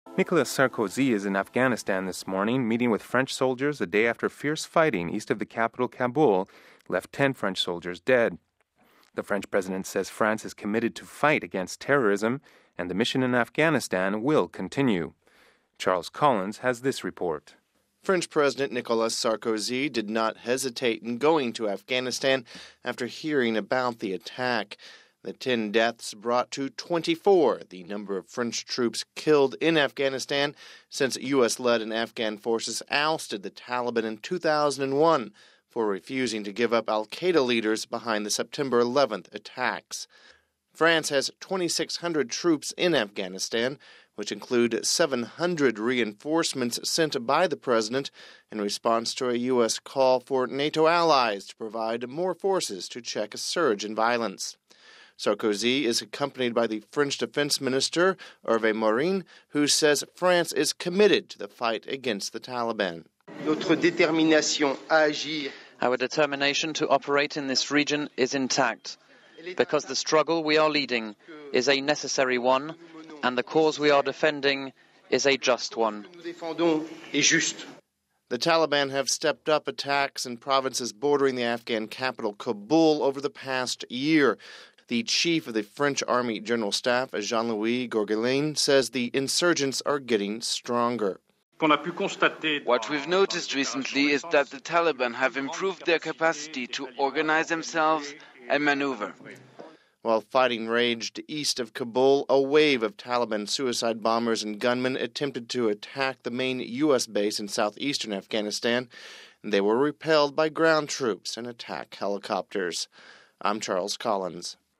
(20 Aug 08 - RV) A day after an attack kills 10 French troops, President Nicolas Sarkozy has gone to Afghanistan. He says France remains committed to the war against the Taliban. We have this report...